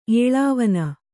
♪ ēḷāvana